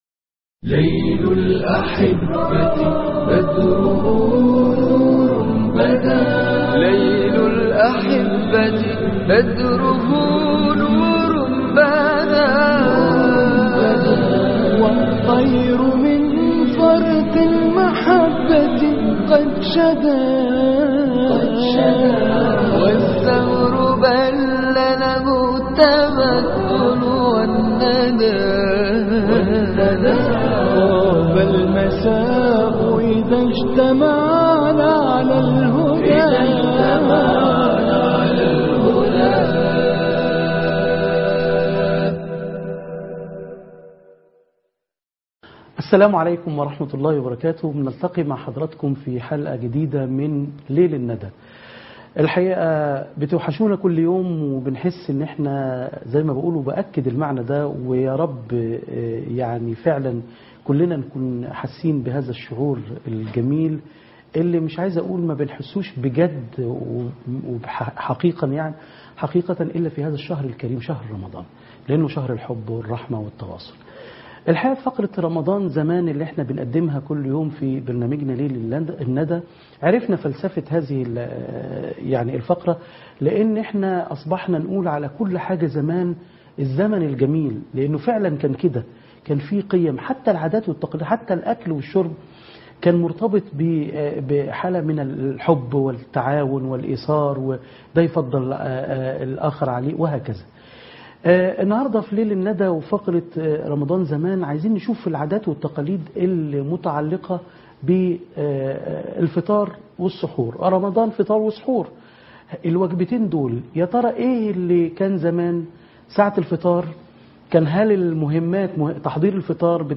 لقاء